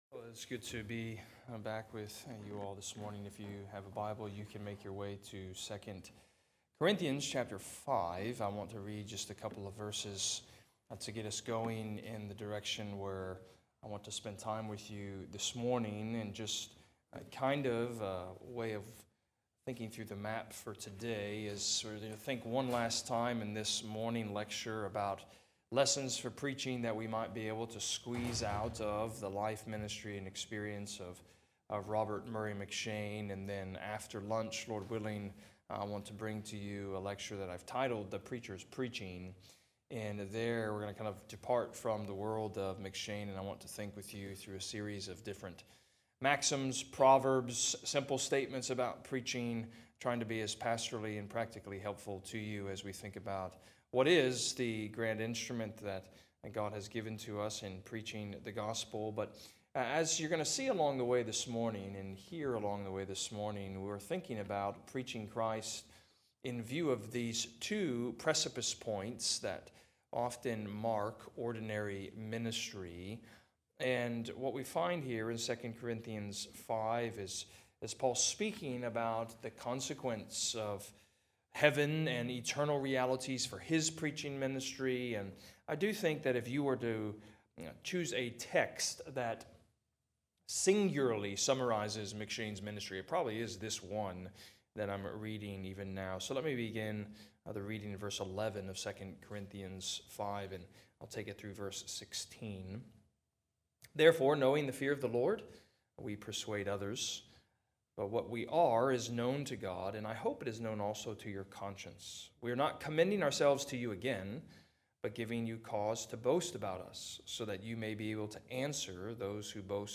RTS Jackson 2025 John Reed Miller Lectures Part 3